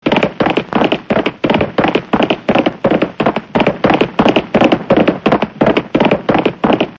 Zwierzęta